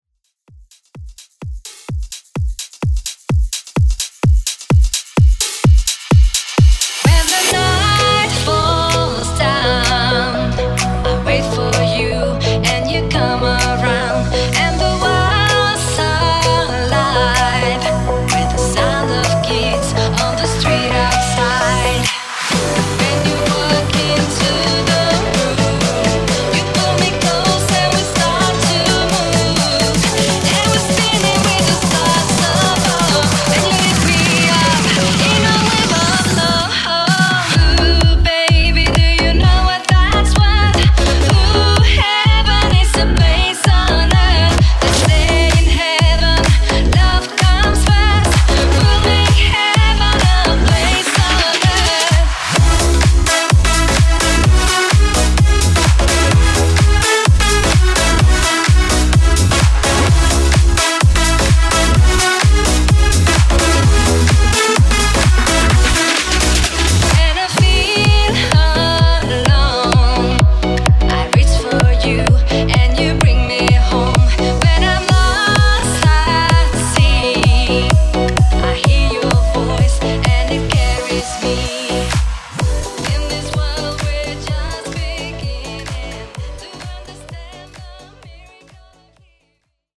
Genres: 90's , RE-DRUM
Clean BPM: 100 Time